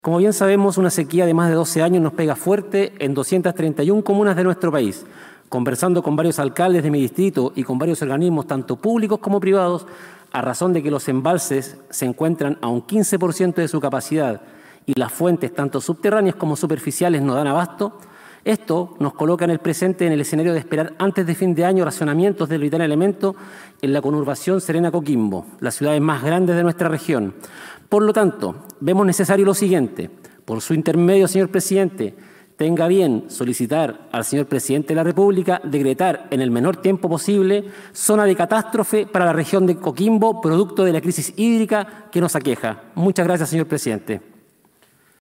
Durante una intervención que realizó este miércoles en plena sesión de la Cámara de Diputadas y Diputados, el parlamentario del Distrito 5, Víctor Pino Fuentes (PDG) , solicitó que se oficie al Presidente de la República, Gabriel Boric, para decretar Zona de Catástrofe a la región de Coquimbo por la grave crisis hídrica que enfrenta la zona.
DIPUTADO-PINO.mp3